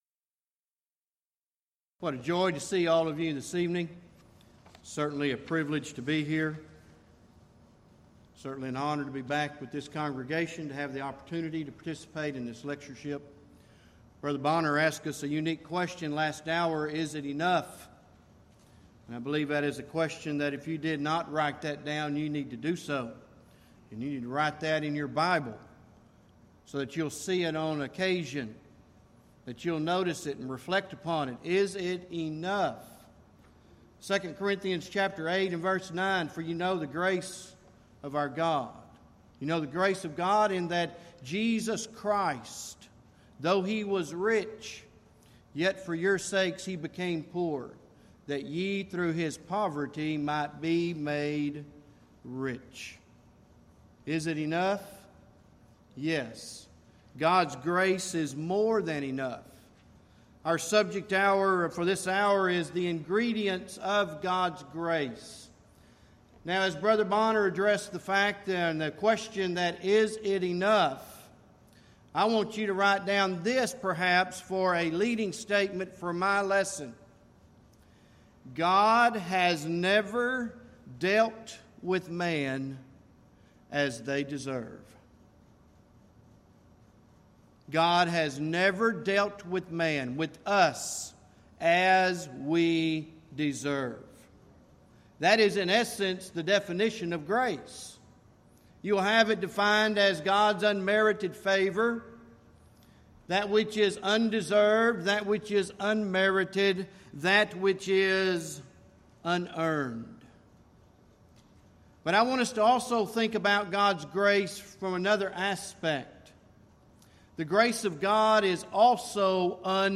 Event: 21st Annual Gulf Coast Lectures Theme/Title: The Amazing Grace of God
lecture